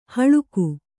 ♪ haḷuku